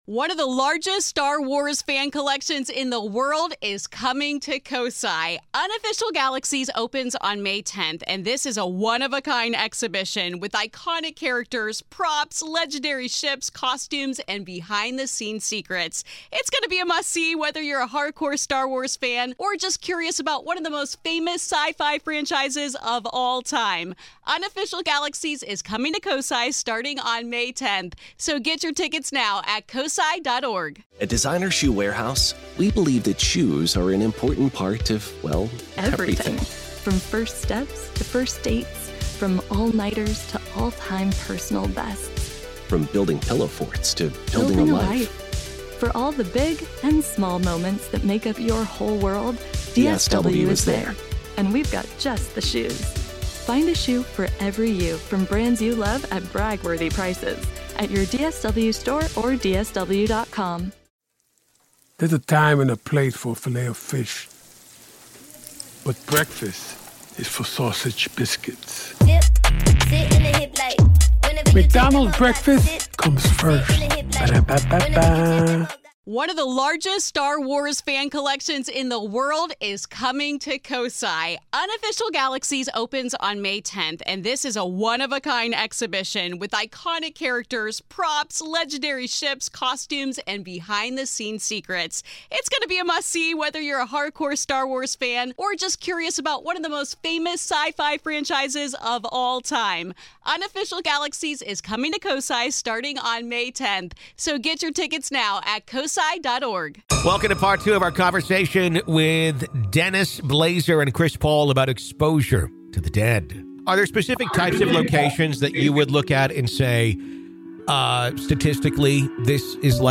Expect stories of roadside apparitions, graveyard stakeouts, and the kind of EVP clips that make you double-check your locks. This is Part Two of our conversation.